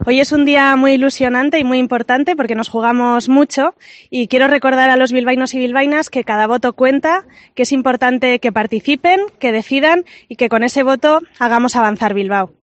Tras depositar las papeletas en el barrio de Irala, la candidata socialista ha asegurado que el tiempo animará a la ciudadanía a acudir a las urnas
Nora Abete, candidata socialista a la alcaldía de Bilbao